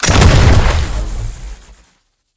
rifle2.ogg